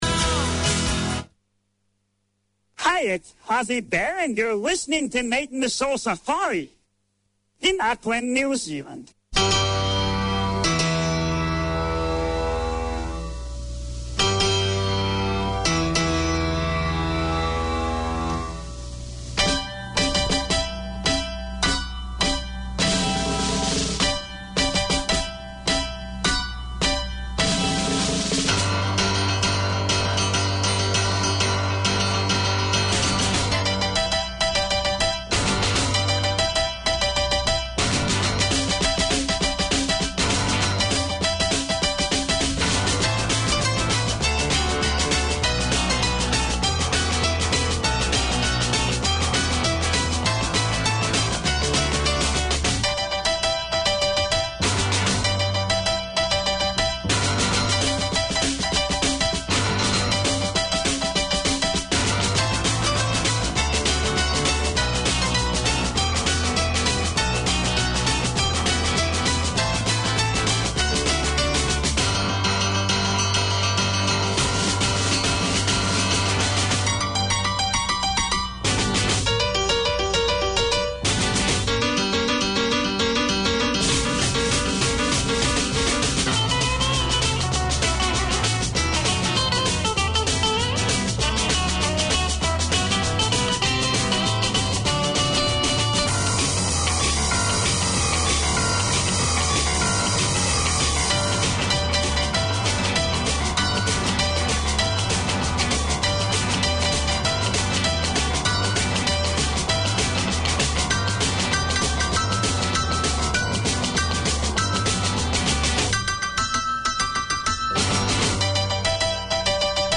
A combination of modern and organic motifs, Art Deco design continues to be captivating and collectable. Deco is the passion of the presenters of this programme that explores the local and global Deco scene, preservation and heritage, the buildings, jewellery and furnishings with interviews, music, notice of coming events; a sharing of the knowledge of Club Moderne, the Art Deco Society of Auckland.